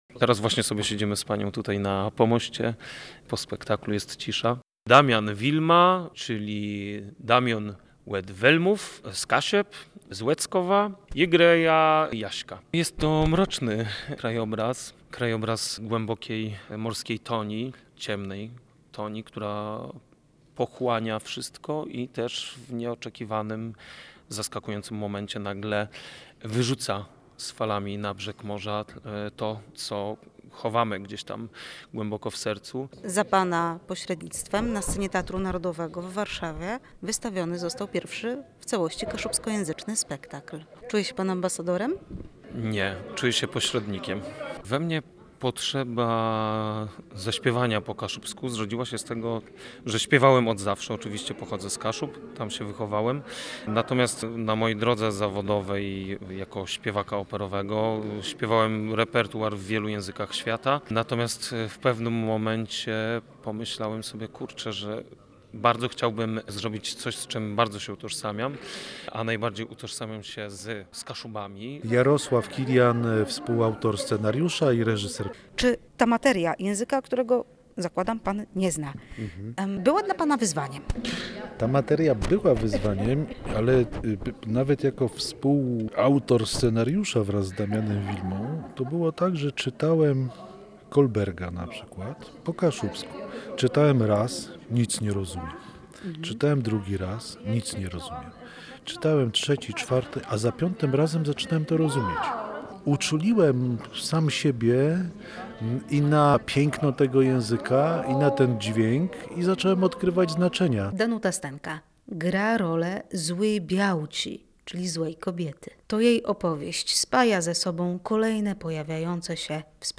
Po spektaklu, na scenie, wśród scenografii z mikrofonem Radia Gdańsk